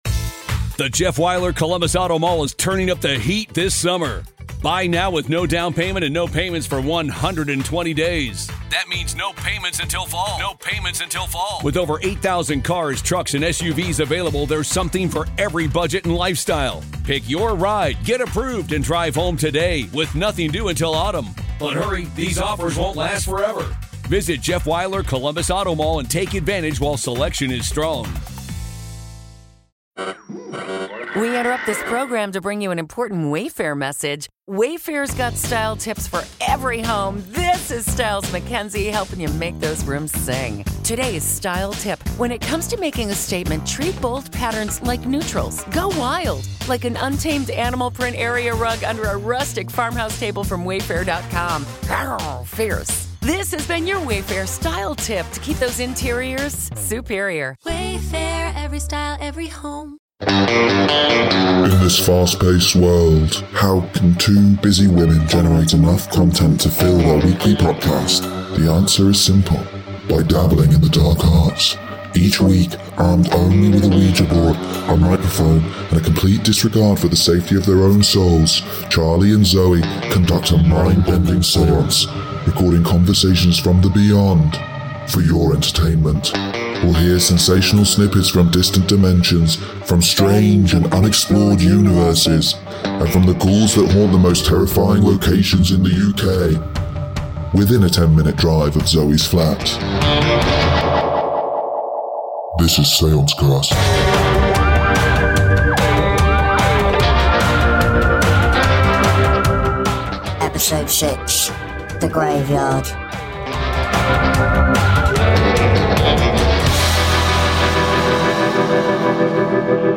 Featuring sketches